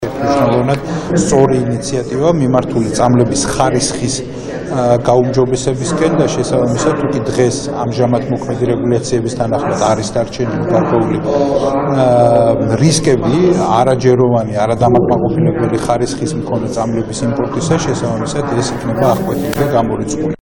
მიხეილ სარჯველაძის ხმა